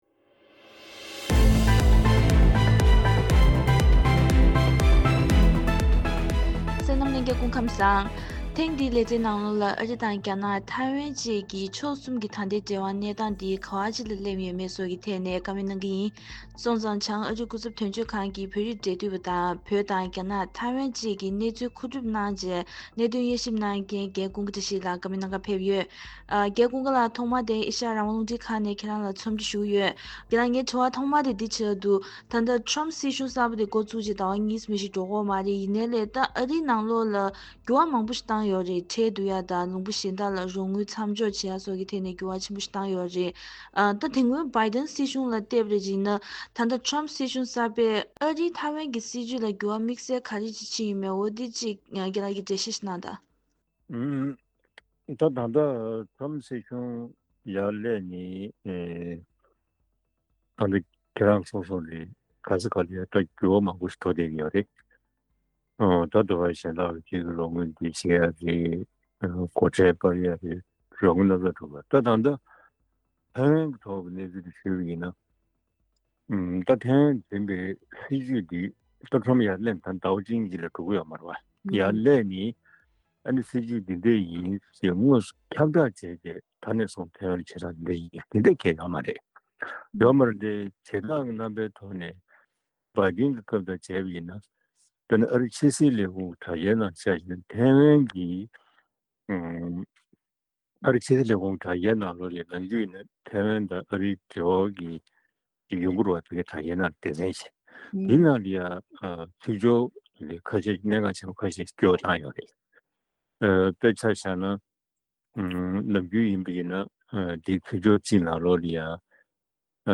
བཀའ་འདྲི་ཞུས་པའི་ལེ་ཚན་ལེ་ཚན་ཞིག